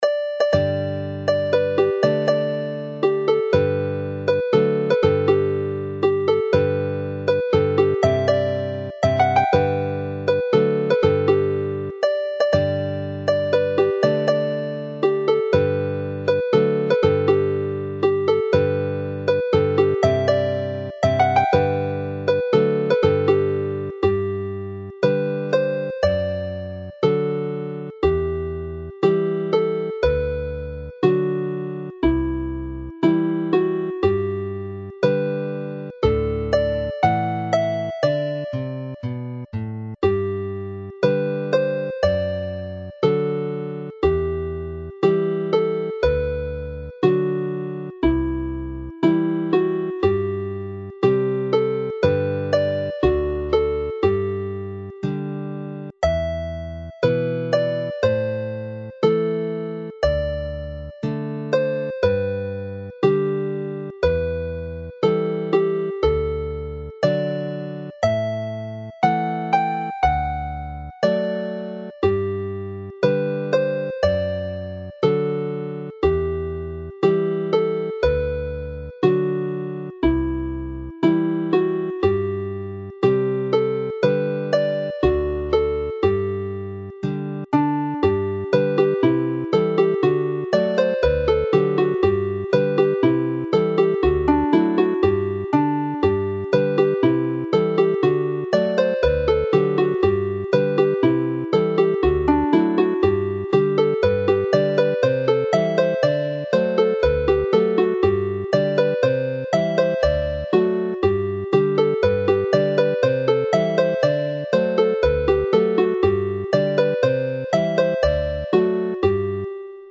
This is a set well suited to the harp.